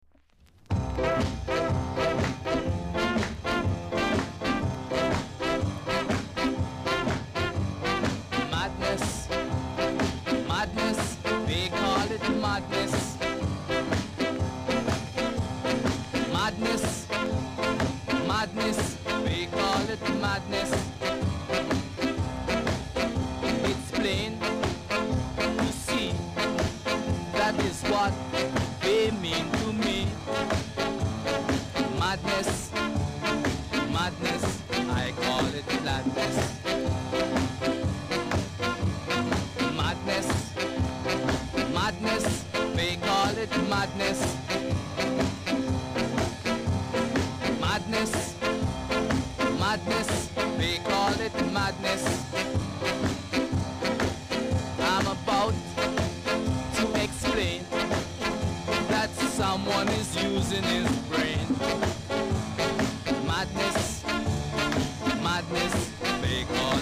※小さなチリノイズが少しあります。
BIG SKA!!